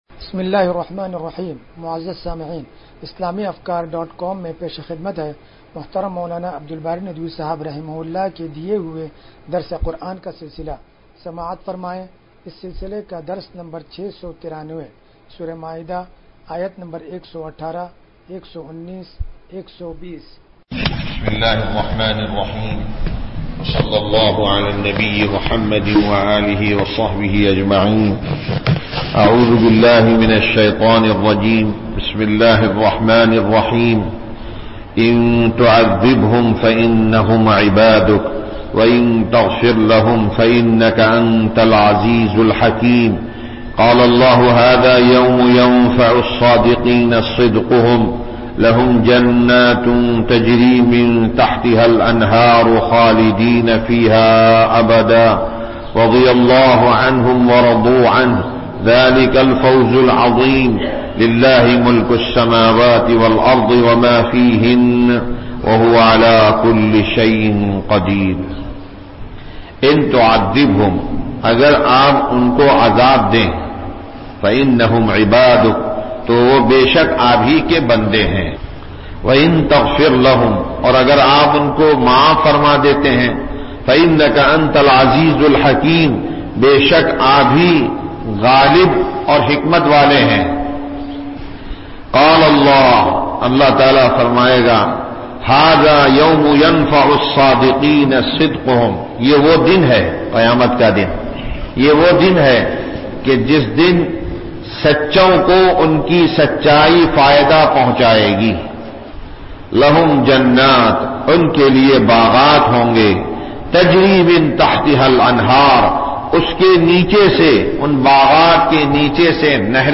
درس قرآن نمبر 0693
درس-قرآن-نمبر-0693.mp3